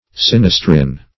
Search Result for " sinistrin" : The Collaborative International Dictionary of English v.0.48: Sinistrin \Sin"is*trin\ (s[i^]n"[i^]s*tr[i^]n), n. [L. sinister left.]